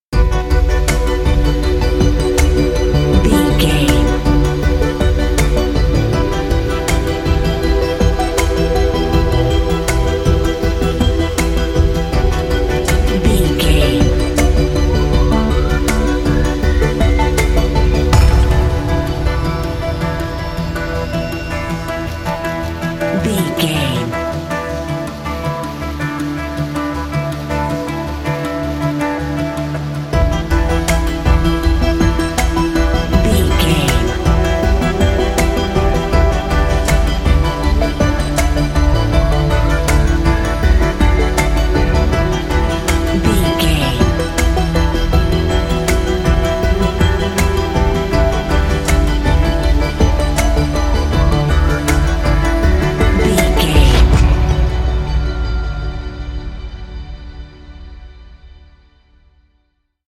In-crescendo
Thriller
Aeolian/Minor
Slow
drum machine
synthesiser
ominous
dark
suspense
haunting
creepy